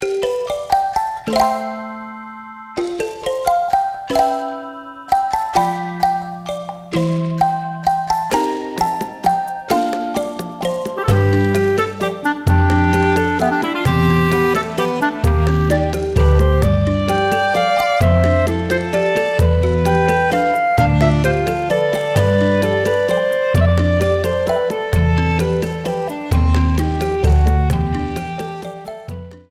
Ripped from the game
trimmed to 29.5 seconds and faded out the last two seconds